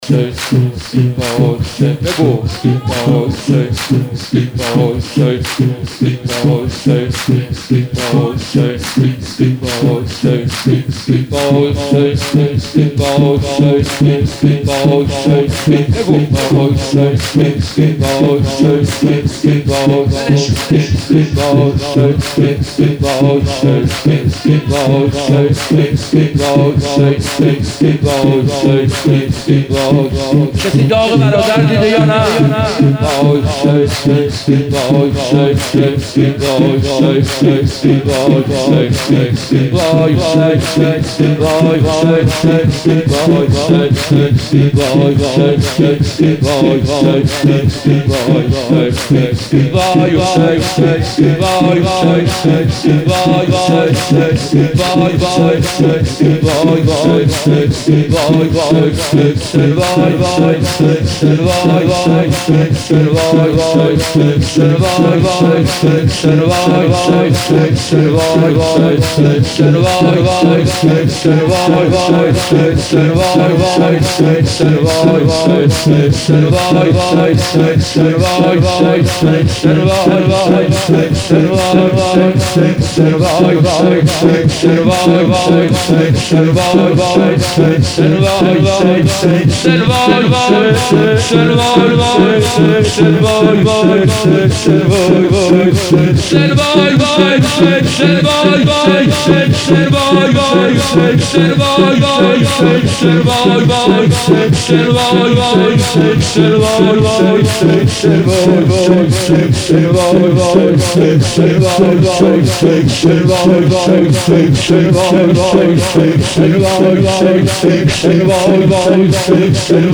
قالب : شور